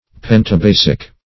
Search Result for " pentabasic" : The Collaborative International Dictionary of English v.0.48: Pentabasic \Pen`ta*ba"sic\, a. [Penta- + basic.]